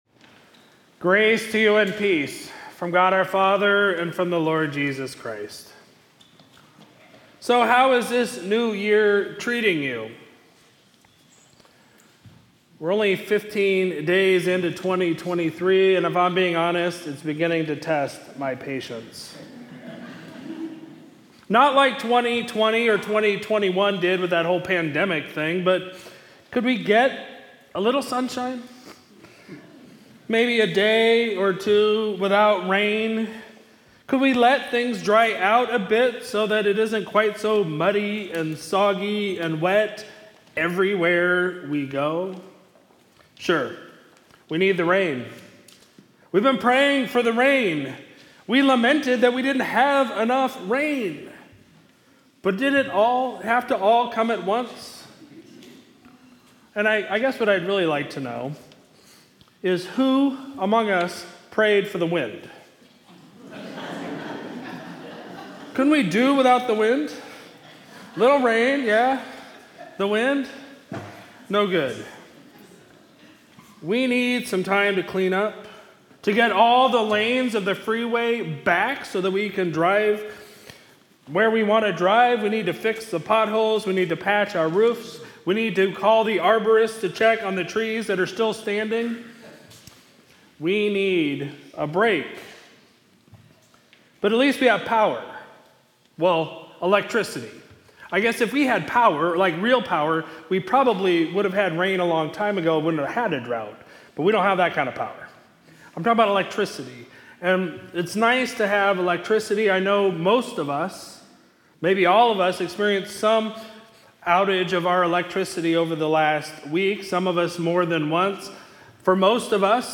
Sermon for Sunday, January 15, 2023